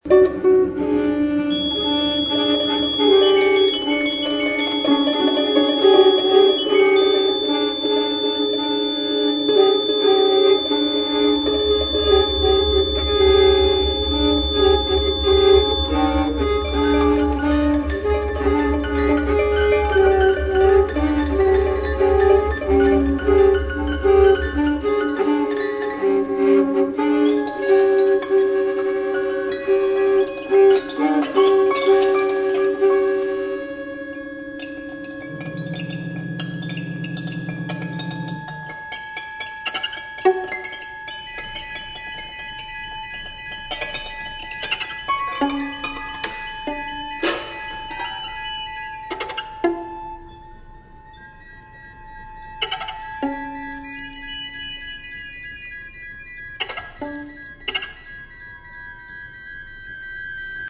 experimental, improvisation